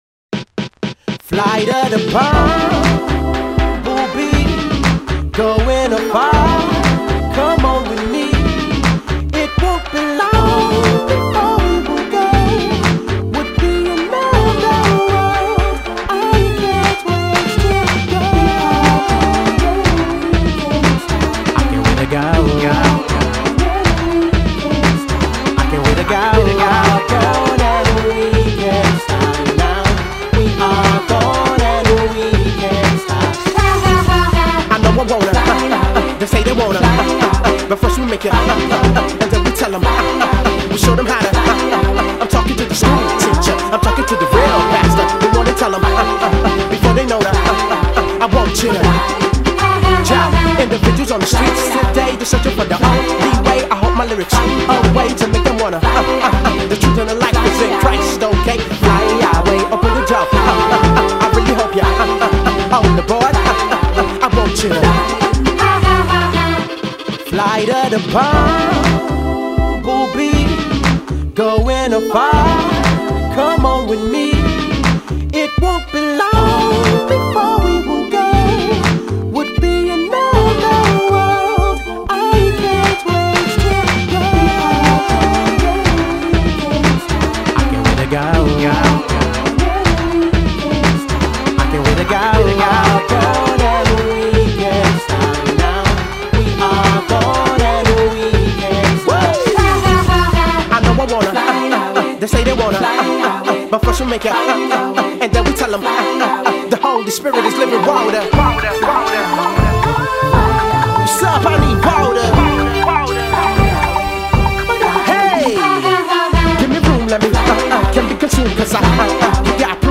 When an ‘out of the box’ kind of rapper
teams up with a world class violinist